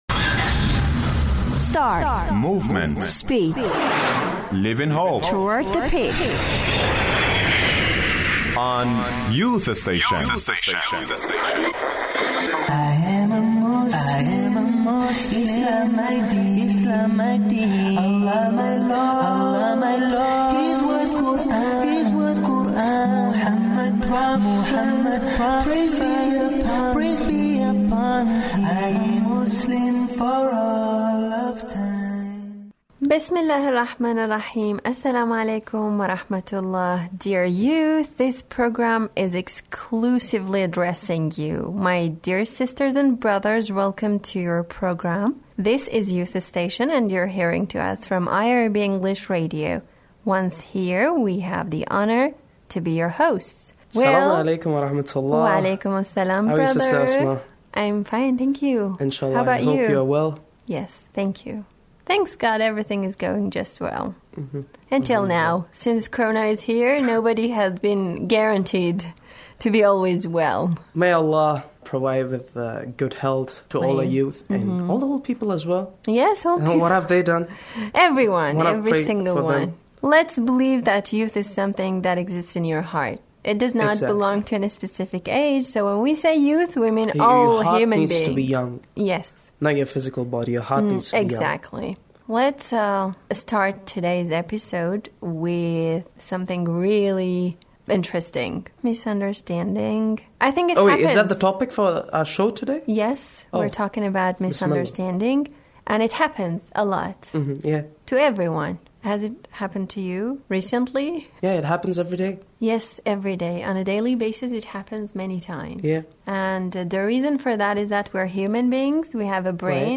The youths talk on Misundrestanding